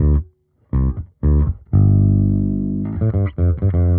Index of /musicradar/dusty-funk-samples/Bass/120bpm
DF_JaBass_120-D.wav